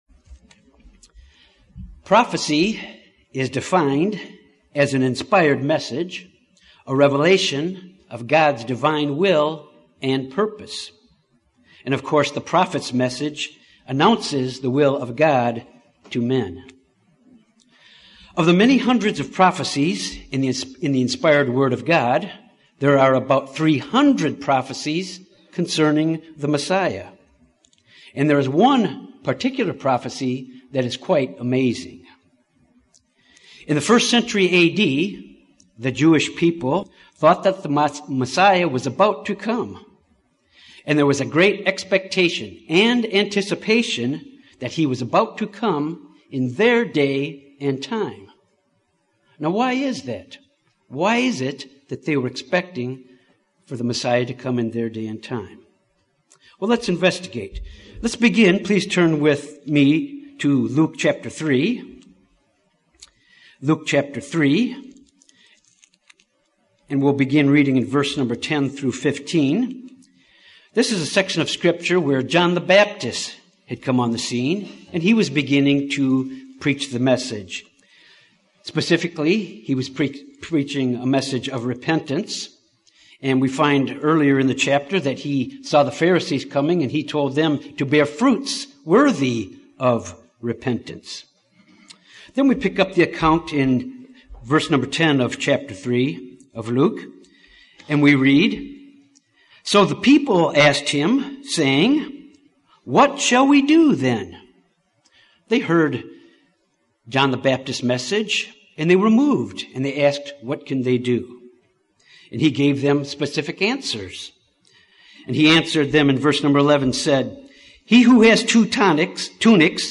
During the time of Jesus, the people knew that the coming of the Messiah was near according to the prophecy of Daniel. This sermon examines the seventy week prophecy of Daniel.